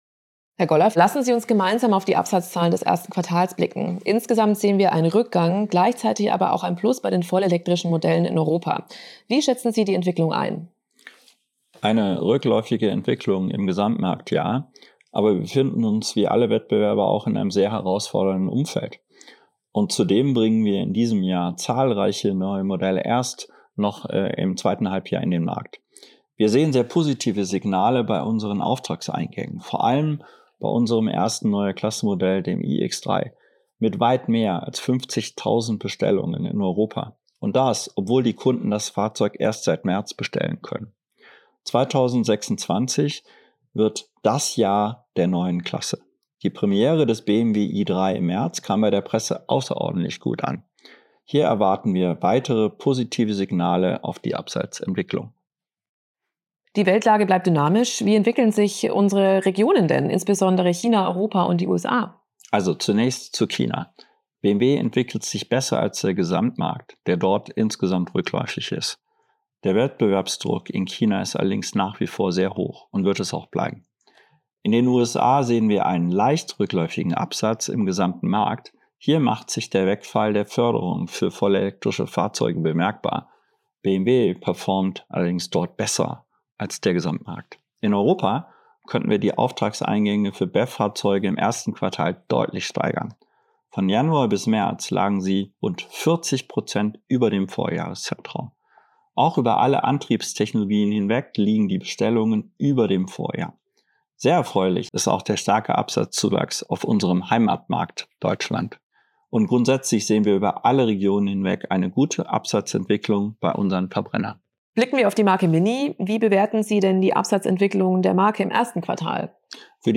O-Ton